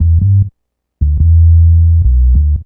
Bass 42.wav